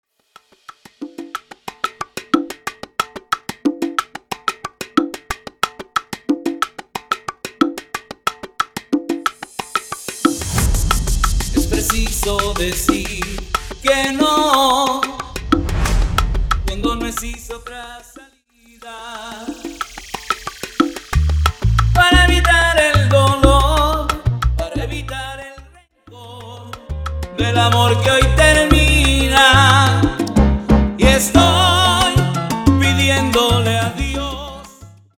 Intro Acapella Dirty